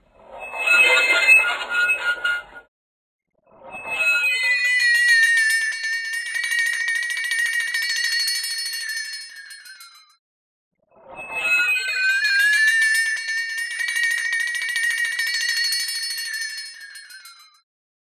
In this last example, a metalic scraping sound is transformed into a flexitone (a percussion instrument sometimes used to make eerie sound effects). Two slightly different morphs are presented.
All sound morphs and syntheses presented here were created using the open source Loris software for sound analysis, synthesis, and manipulation.
flexiscrape.wav